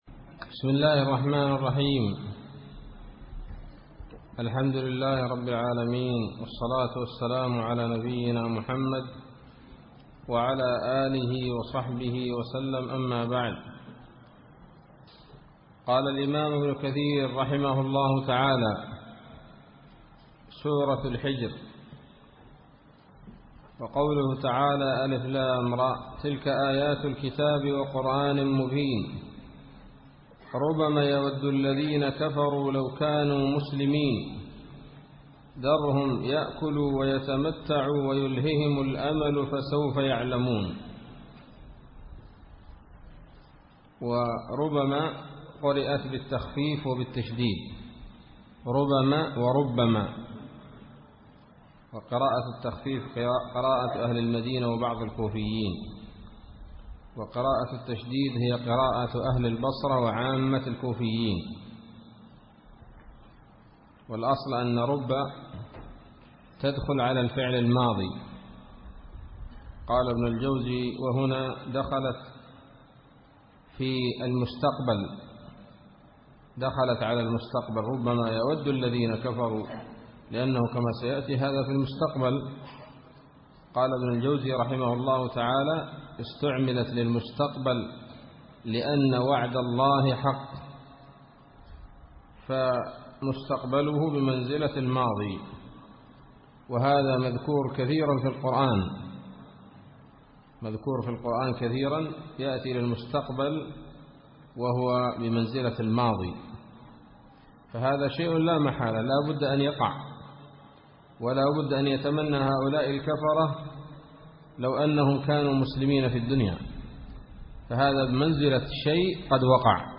الدرس الأول من سورة الحجر من تفسير ابن كثير رحمه الله تعالى